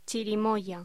Locución: Chirimoya